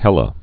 (hĕlə)